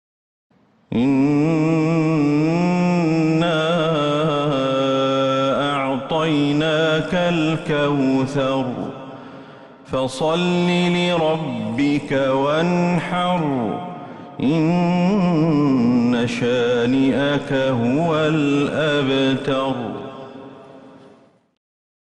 سورة الكوثر Surat Al-Kawthar > المصحف المرتل من المسجد النبوي > المصحف - تلاوات الشيخ أحمد الحذيفي